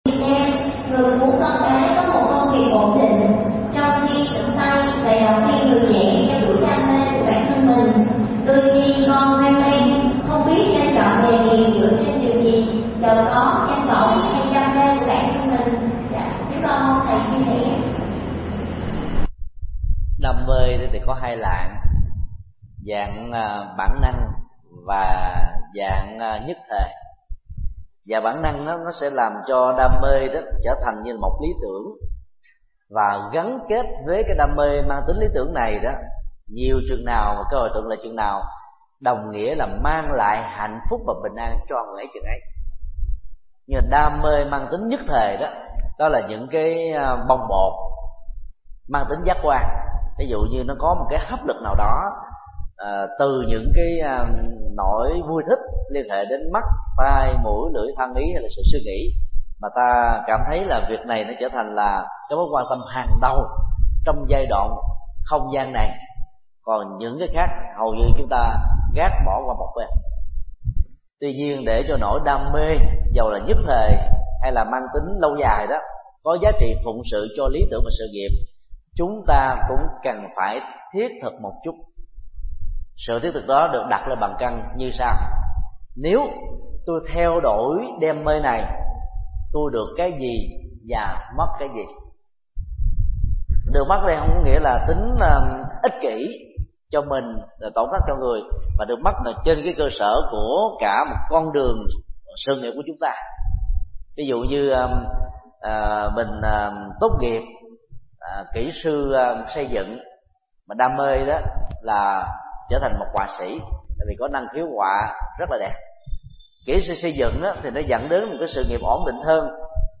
Vấn đáp: Đam mê và lý tưởng trong sự nghiệp